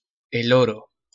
Spanish pronunciation of El Oro
Pronunciación de El Oro en español
ES-pe_-_El_oro.ogg